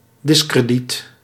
Ääntäminen
Ääntäminen US : IPA : [dɪs.ˈkɹɛd.ɪt]